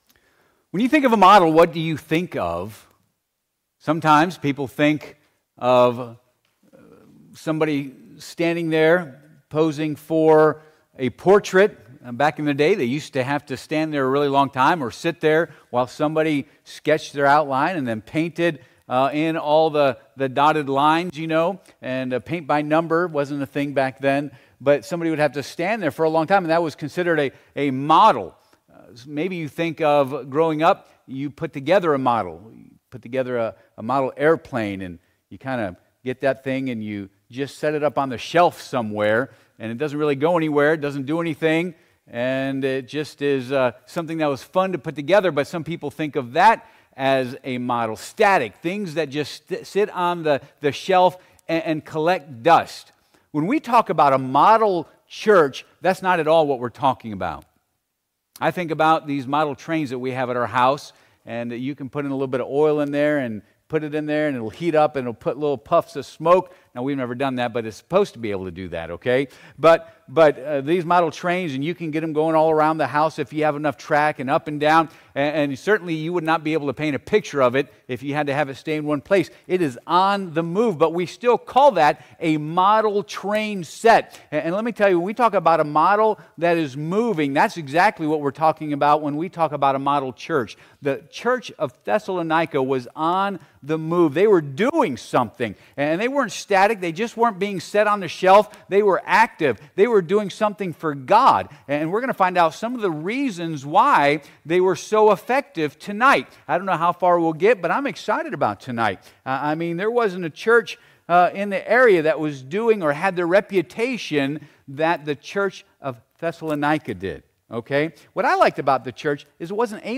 Service Type: Midweek Service